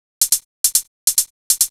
Techno / Drum / HIHAT023_TEKNO_140_X_SC2.wav